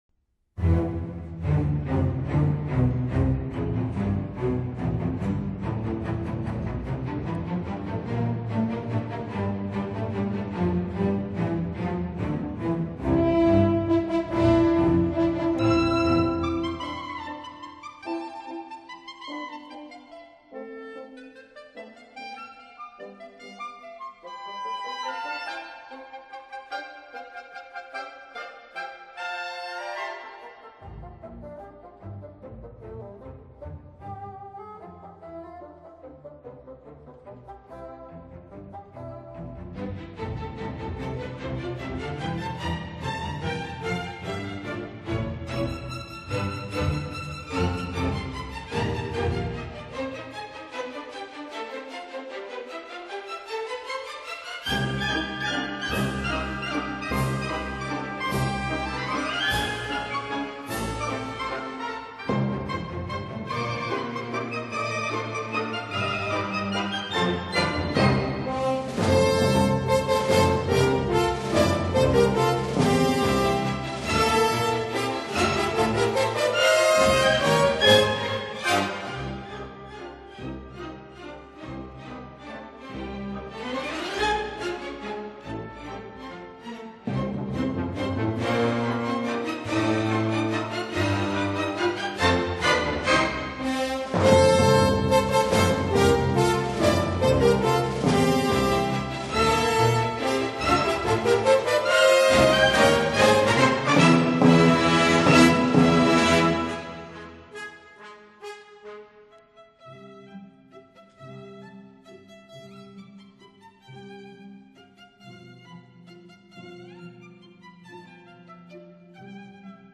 分辑：CD1-CD11 交响曲全集